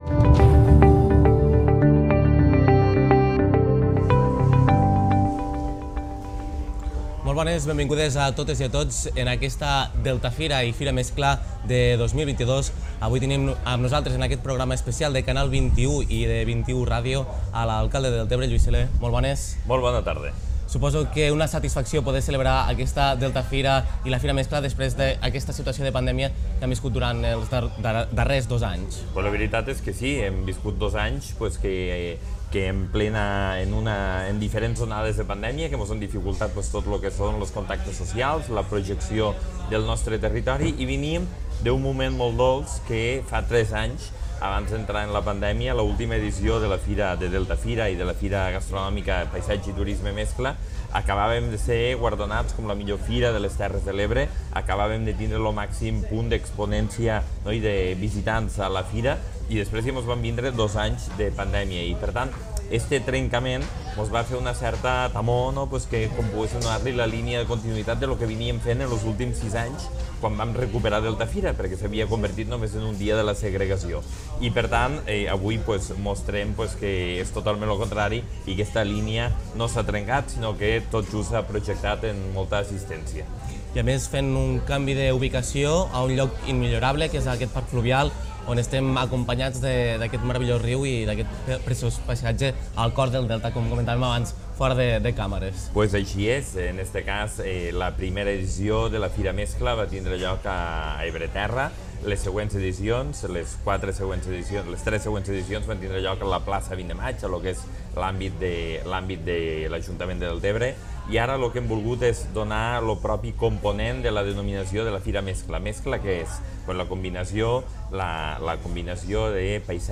Presentació i entrevista a l'alacalde de Deltebre Lluís Soler feta a la DeltaFira i Fira Mescla 2022
Informatiu